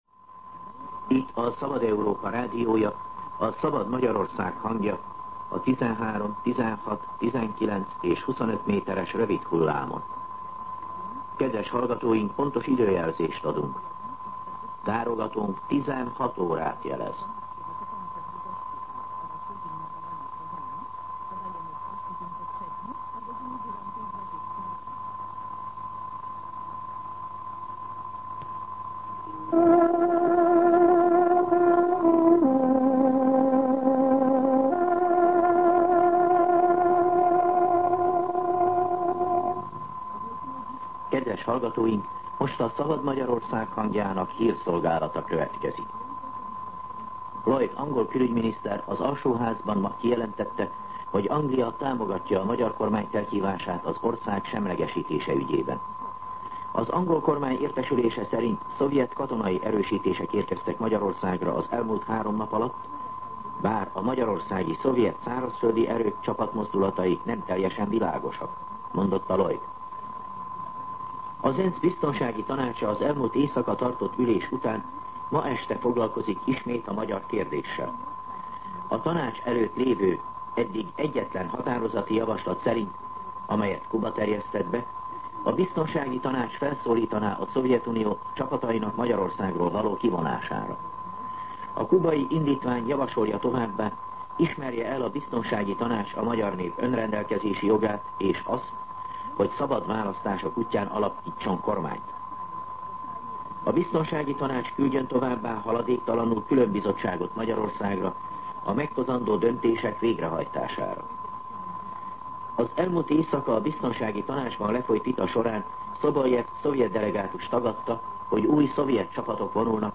16:00 óra. Hírszolgálat